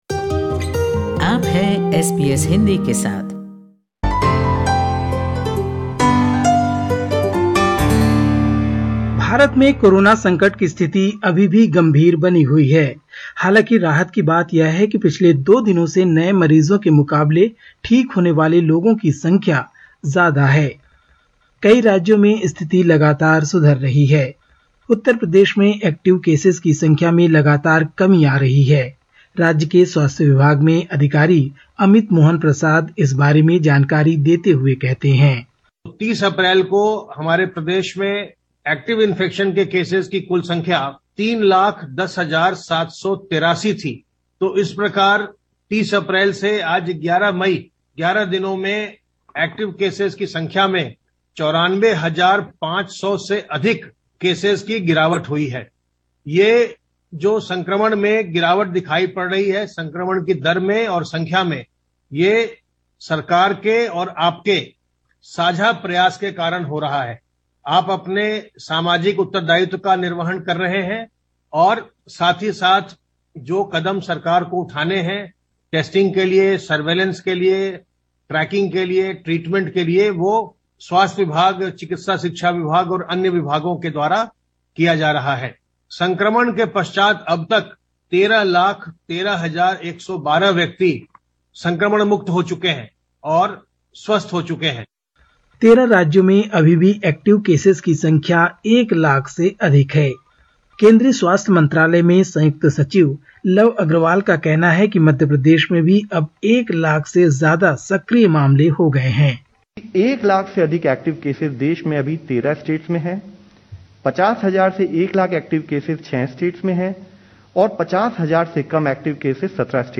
Catch the latest news from India in Hindi: 12/05/21